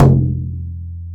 BODHRAN 3A.WAV